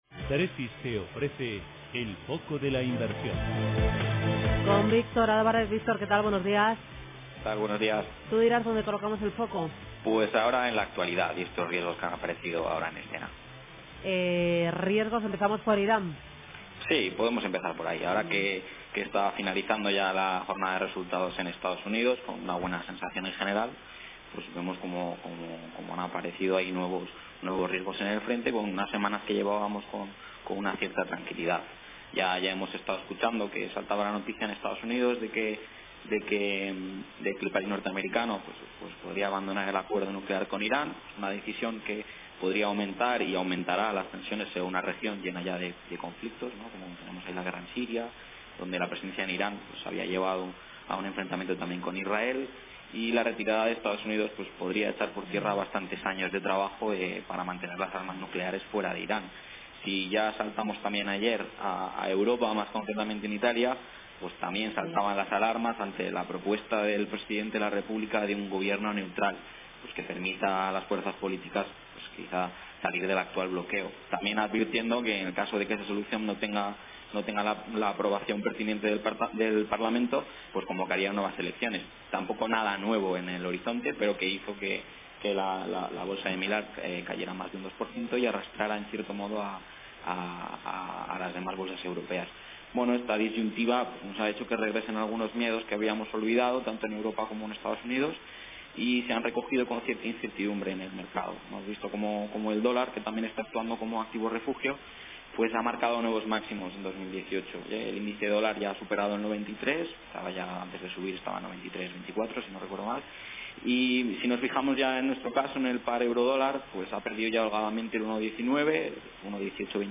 En Radio Intereconomía todas las mañanas nuestros expertos analizan la actualidad de los mercados.